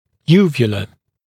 [‘juːvjələ][‘йу:вйэлэ]нёбный язычок